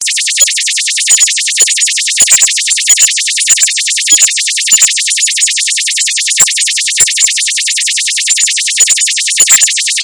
移动电话铃音" 模拟电话
描述：铃声波利托诺
Tag: 消息 呼叫 电话 蜂窝 警报 移动 手机 UEM